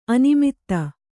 ♪ animitta